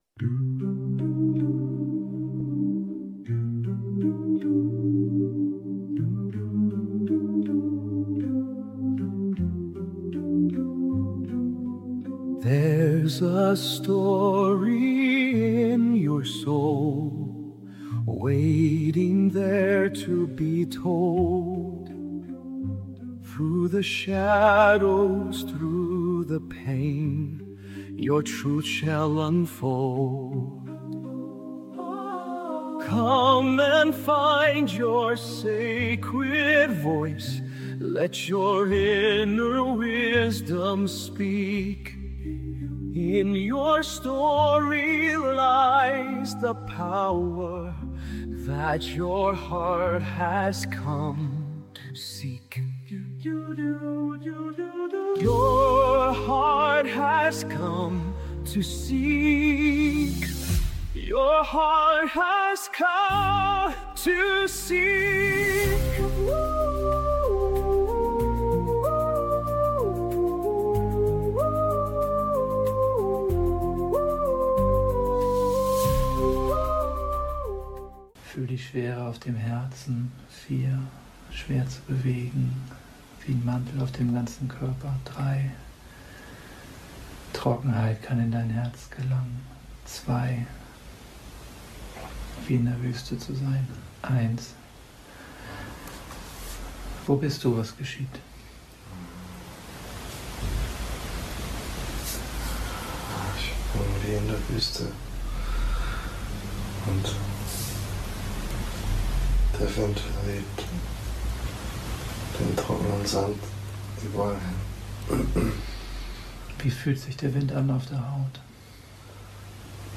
In diesem Live-Talk sprechen wir darüber, was diese Arbeit bedeutet – und welche anderen Türen es gibt, die nach Hause führen.
Drei Menschen.